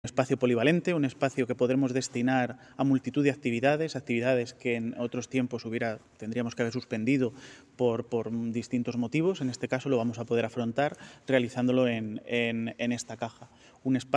Declaraciones del alcalde Miguel Óscar Aparicio 1